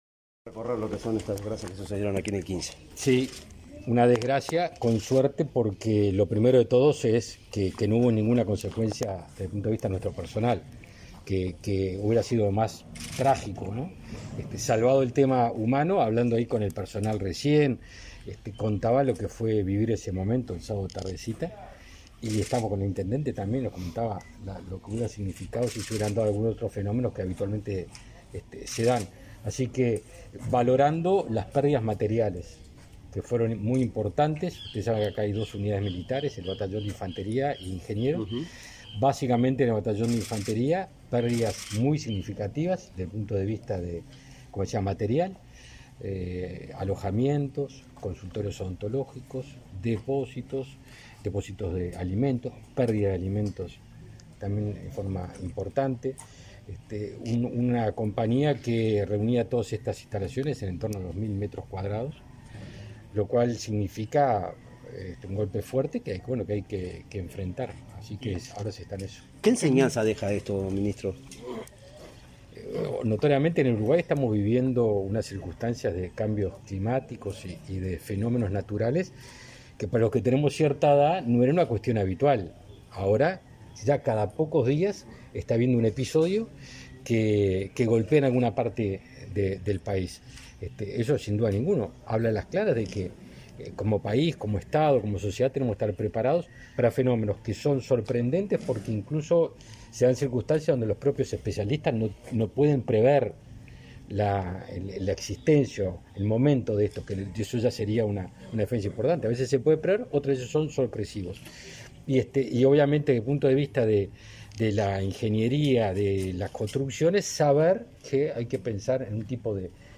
Declaraciones a la prensa del ministro de Defensa, Javier García
Declaraciones a la prensa del ministro de Defensa, Javier García 24/01/2022 Compartir Facebook X Copiar enlace WhatsApp LinkedIn El ministro García recorrió, este 24 de enero, las instalaciones del batallón de Florida afectado por inclemencias climáticas. Tras la visita, el jerarca efectuó declaraciones a la prensa.